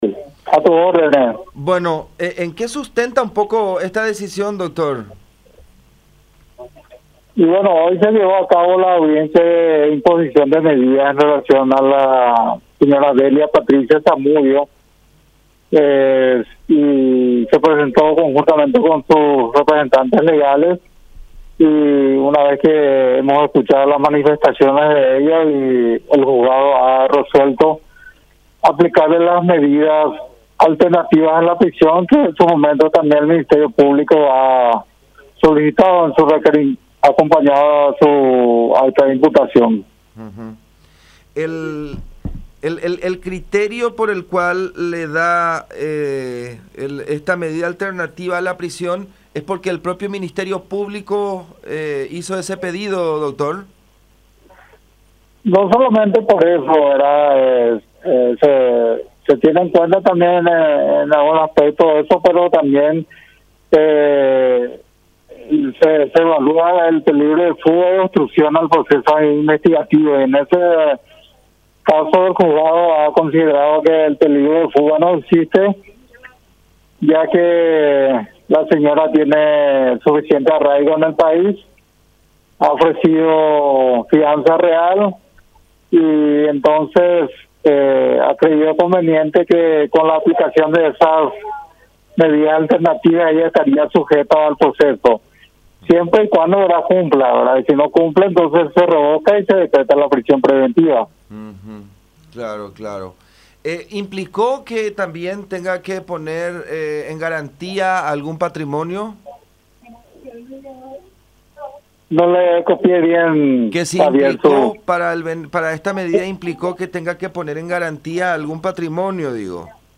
“Se tiene en cuenta varios aspectos y se evalúa el peligro de fuga y la obstrucción a la investigación”, dijo en contacto con La Unión.